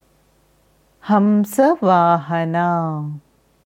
Hamsavahana हंसवाहना haṁsavāhanā Aussprache
Hier kannst du hören, wie das Sanskritwort Hamsavahana, हंसवाहना, haṁsavāhanā ausgesprochen wird: